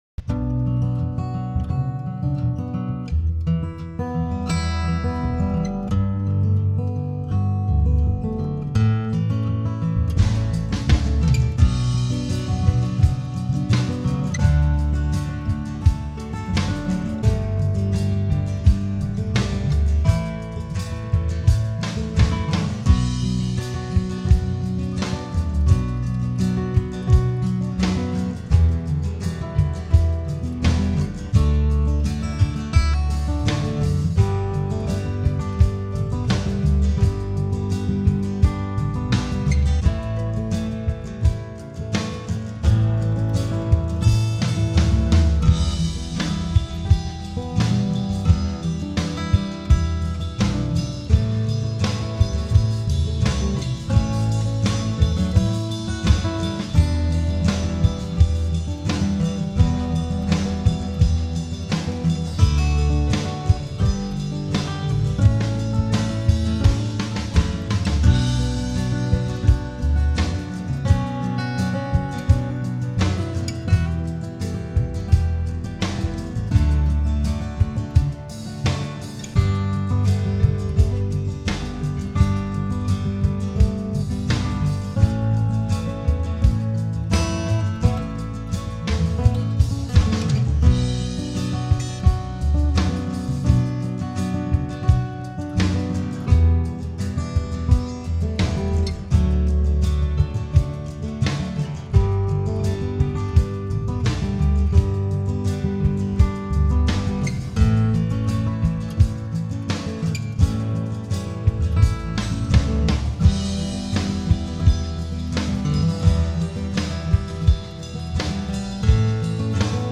Home > Music > Blues > Smooth > Medium > Laid Back